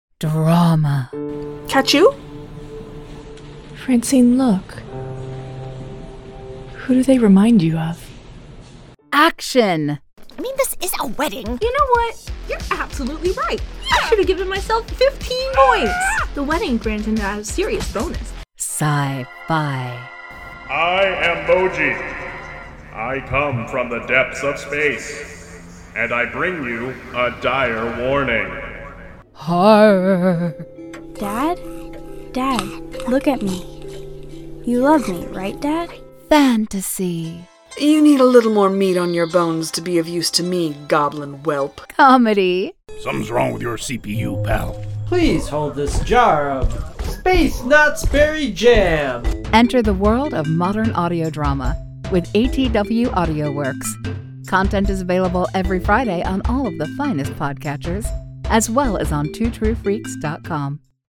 Join the world of modern audio drama with 8TW Audio Works! (1 minute promo)